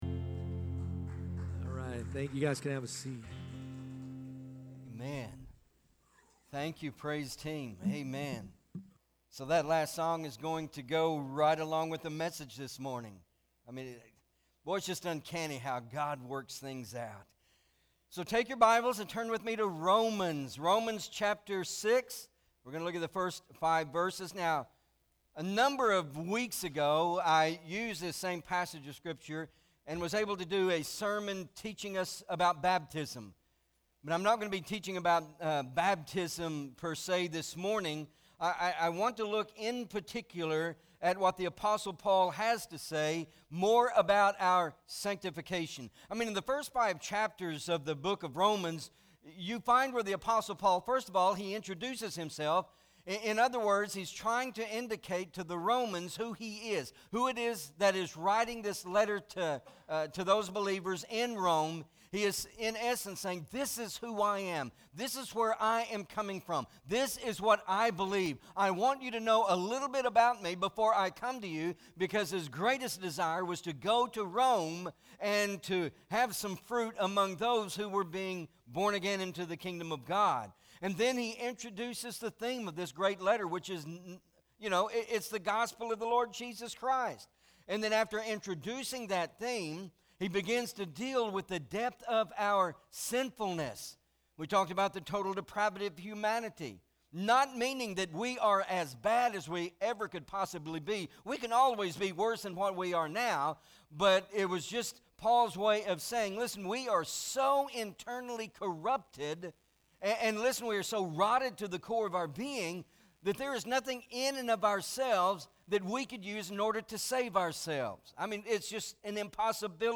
Romans Revealed- Dead to Sin- Alive to God- Pt.1 MP3 SUBSCRIBE on iTunes(Podcast) Notes Sermons in this Series Romans 6:1-5 Not Ashamed!